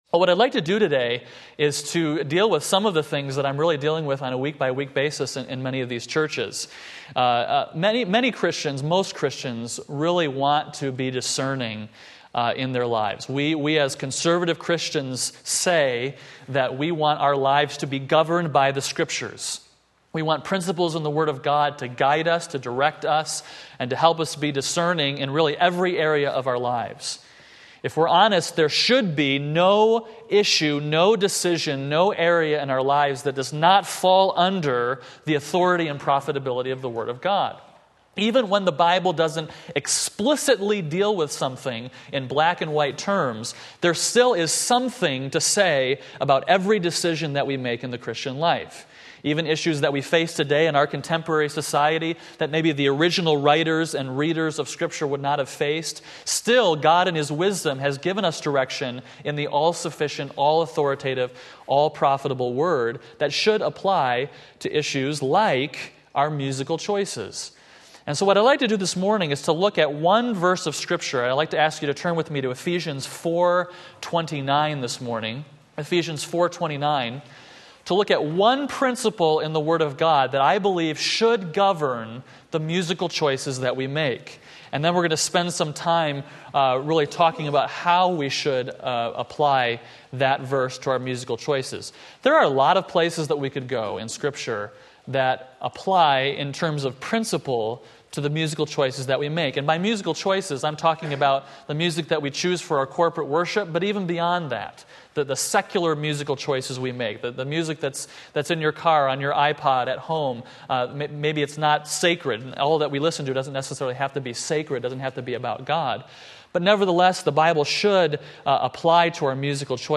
Ephesians 4:29 Sunday School